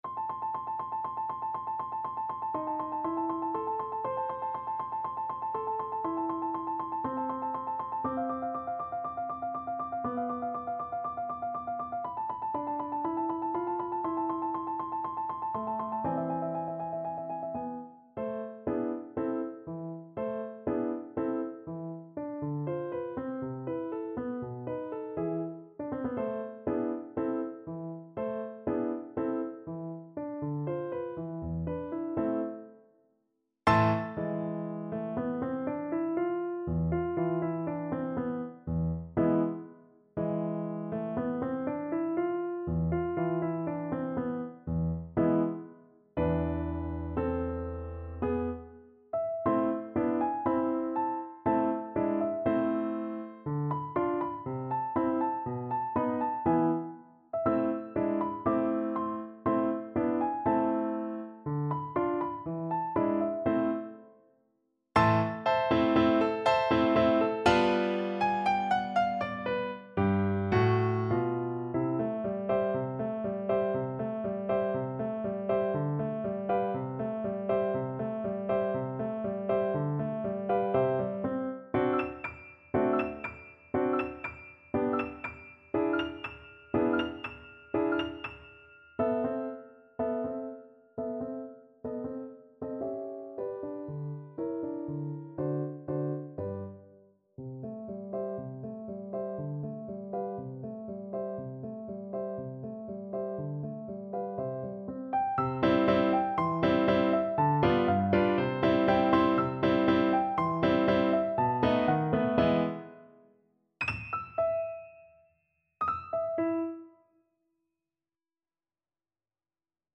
2/4 (View more 2/4 Music)
= 120 Allegro molto vivace (View more music marked Allegro)
Classical (View more Classical Cello Music)